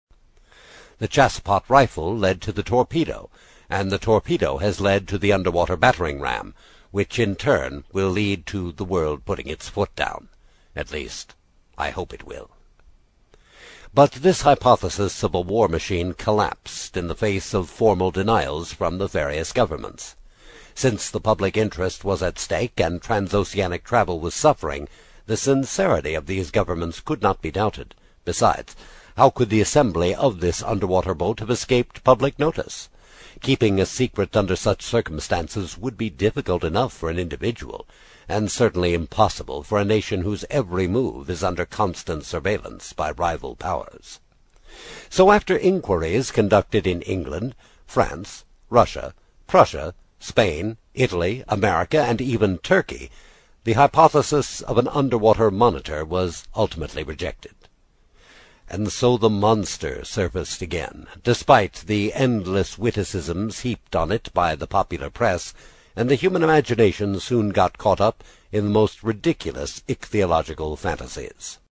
英语听书《海底两万里》第14期 第2章 正与反(3) 听力文件下载—在线英语听力室
在线英语听力室英语听书《海底两万里》第14期 第2章 正与反(3)的听力文件下载,《海底两万里》中英双语有声读物附MP3下载